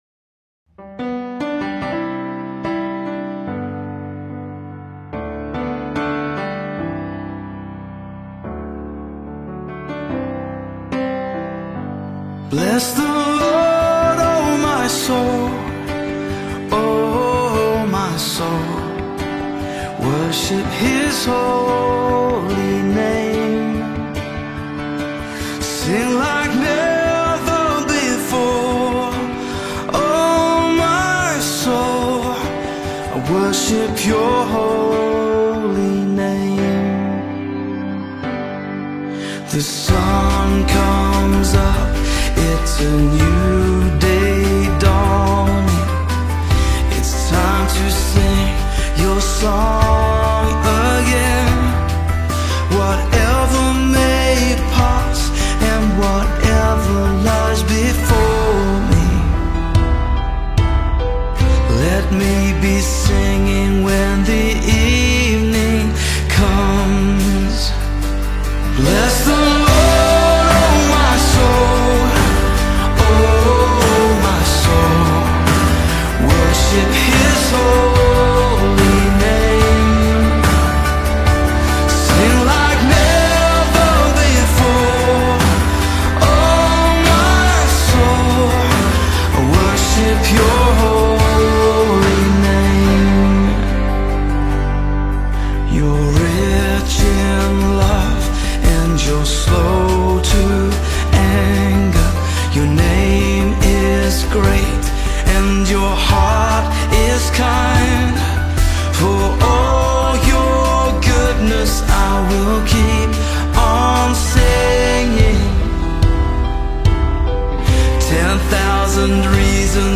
warm, heartfelt vocals
Gospel Songs